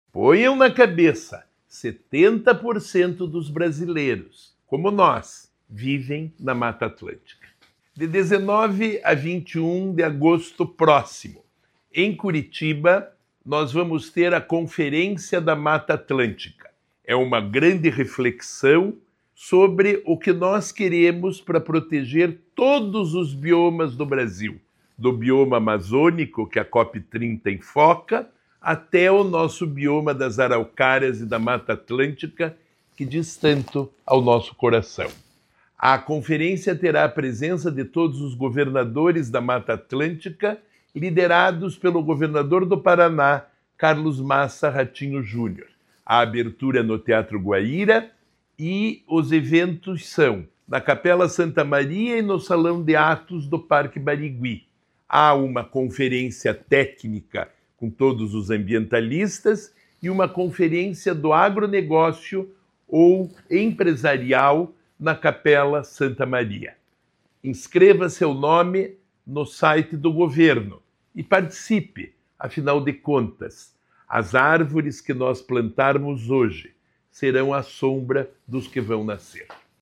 Sonora do secretário de Desenvolvimento Sustentável, Rafael Greca, sobre a Conferência da Mata Atlântica em Curitiba